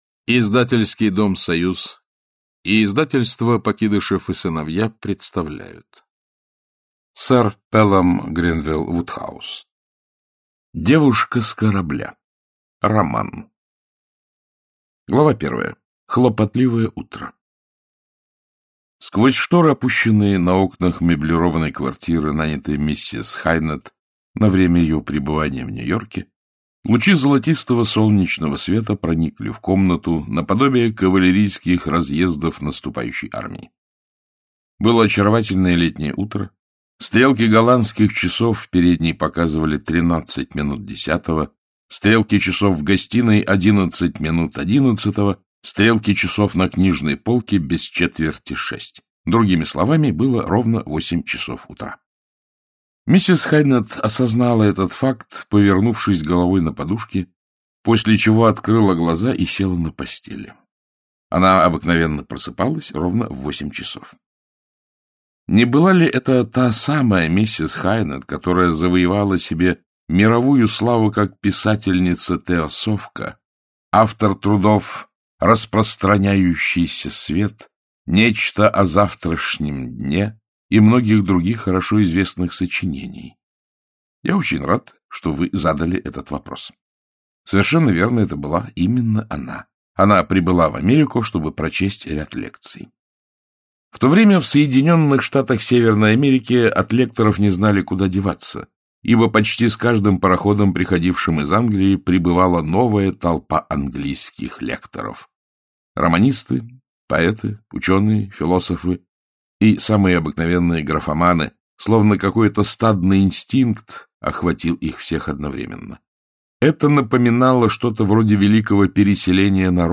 Аудиокнига Девушка с корабля | Библиотека аудиокниг
Aудиокнига Девушка с корабля Автор Пелам Гренвилл Вудхаус Читает аудиокнигу Александр Клюквин.